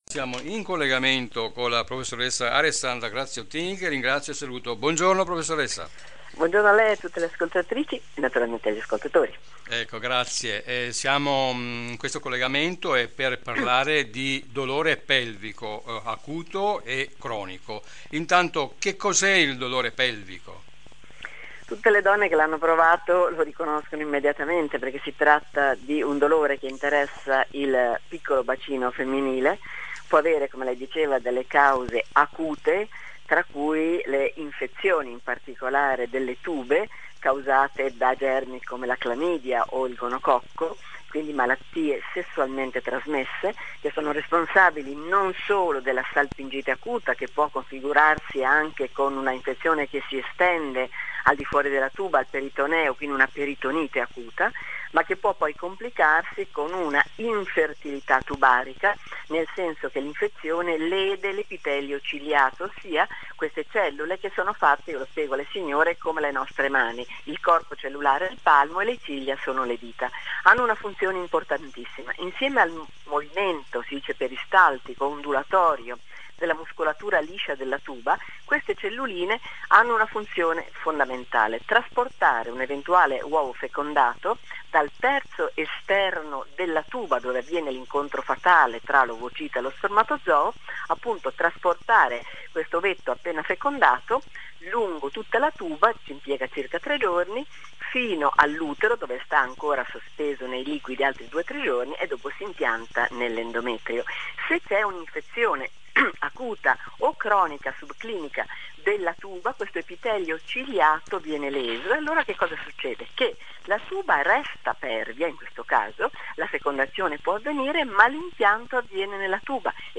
Sintesi dell'intervista e punti chiave
Per gentile concessione di Radio Gamma Cinque